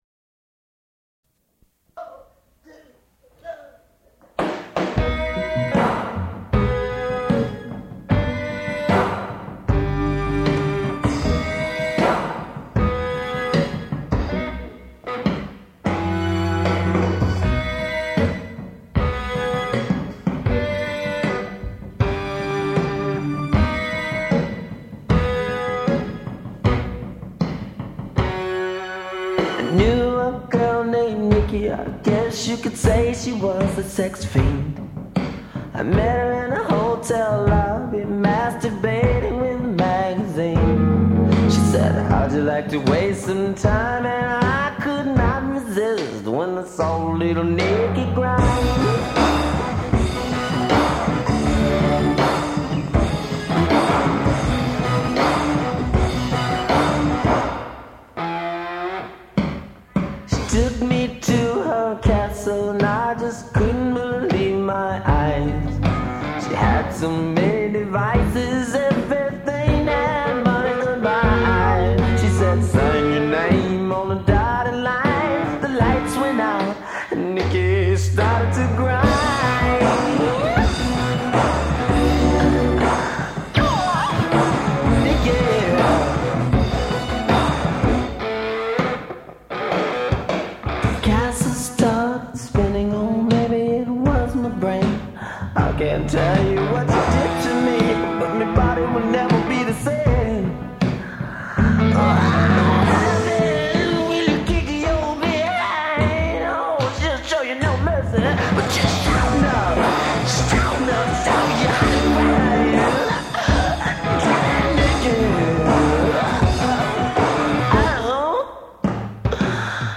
and wide vocal range.